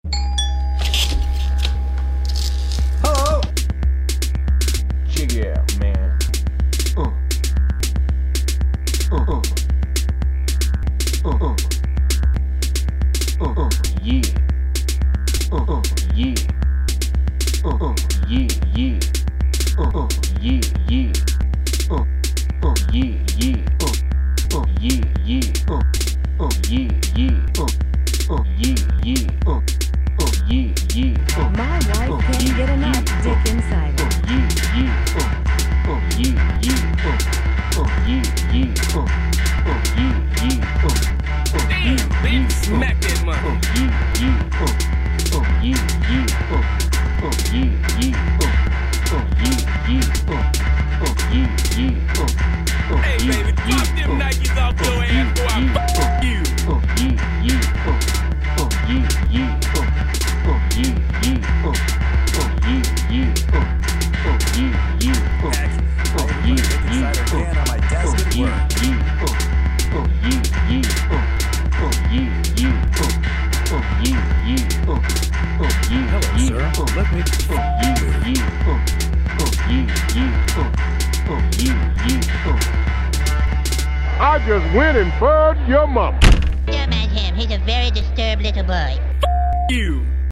Yea Yea, Ugh- This is my attempt at a crappy techno song.